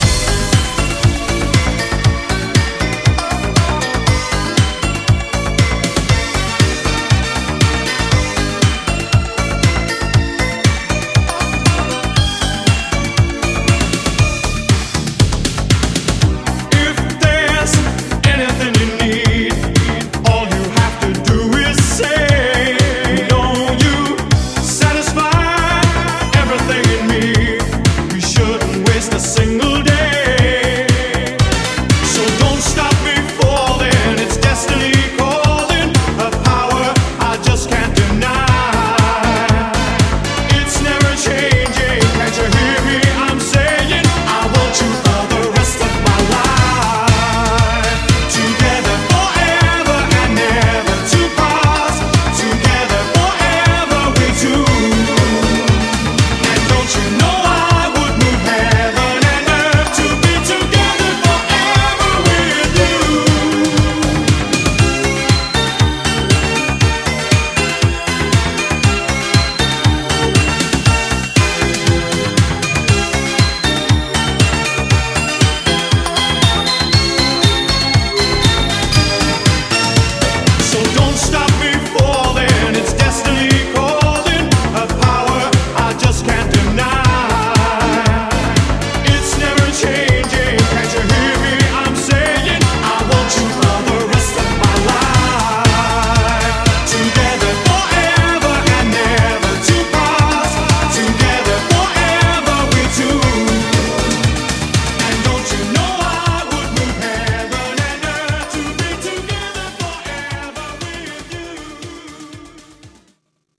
BPM119
Audio QualityPerfect (High Quality)